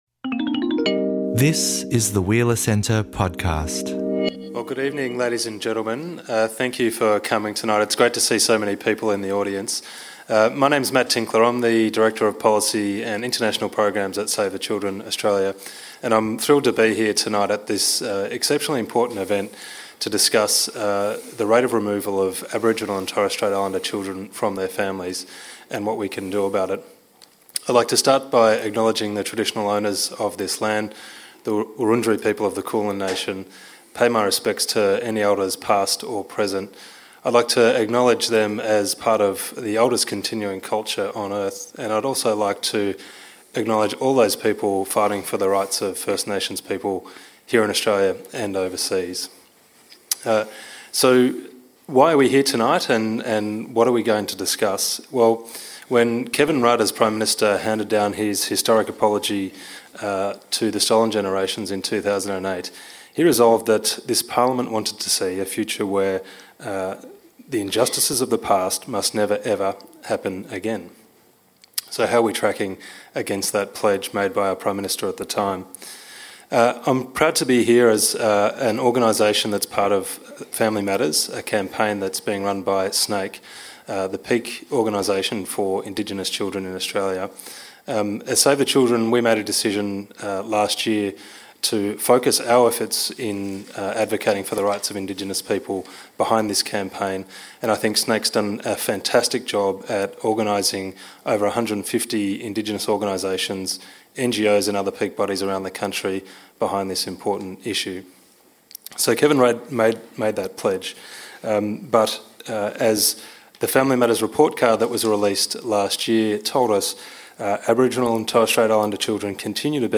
The panel talk about the importance of a Treaty that has specific provisions for Aboriginal kids, and of developing national guidelines rather than state-specific policies and organisations, in this discussion of a critical issue for all Australians.